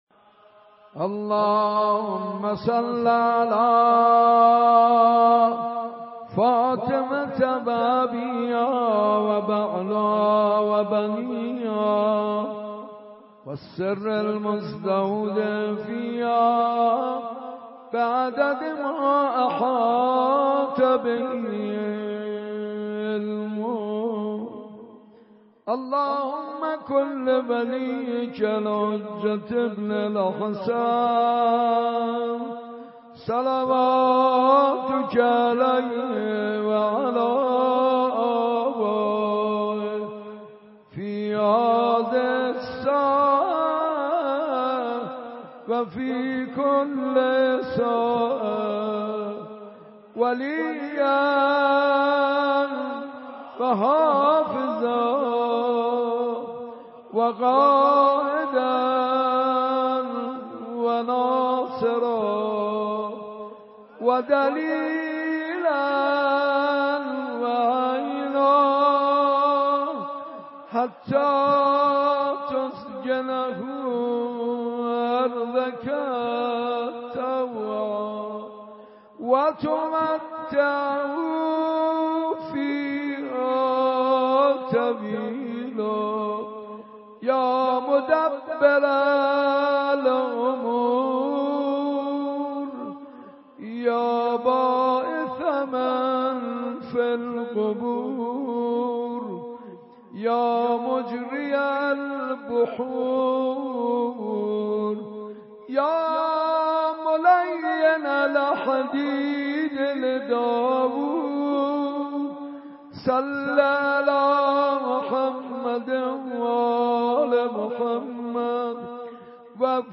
دانلود مناجات خوانی زیبای حاج منصور ارضی مراسم شب بیست و سوم ماه مبارک رمضان۹۷ – مجله نودیها